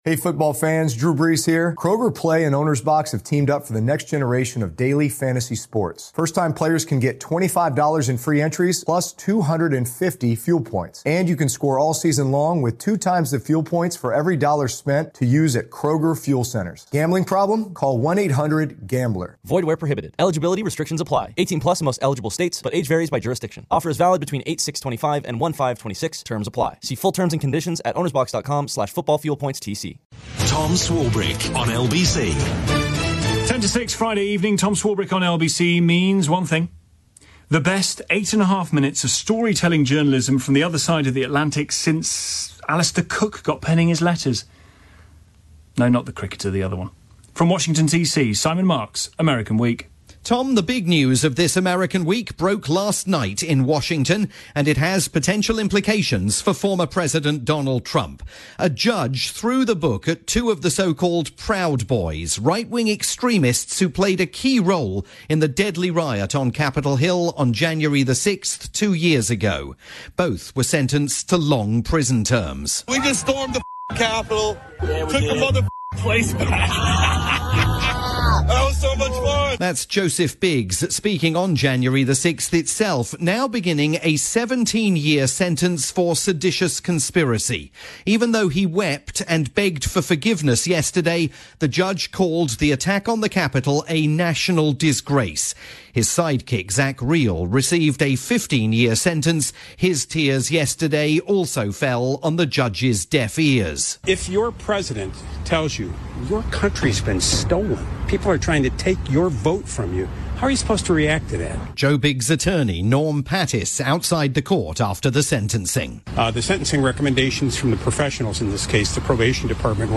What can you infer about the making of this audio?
Friday night drivetime programme for LBC